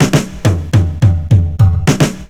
FILL 7    -L.wav